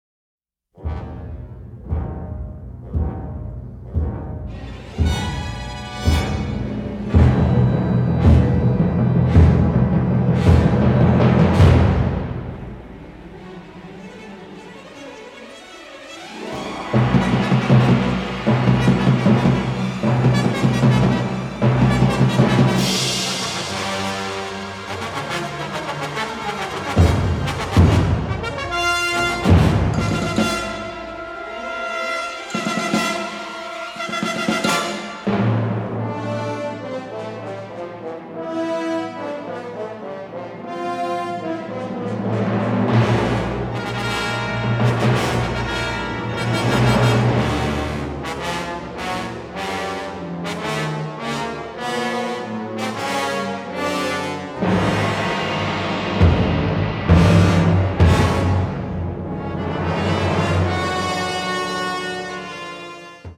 Film Music Genre